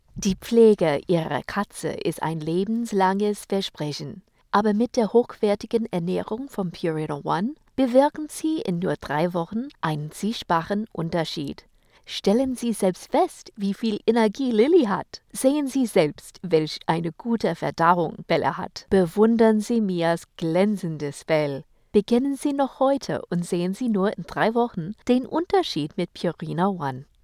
Commercial (deutsch):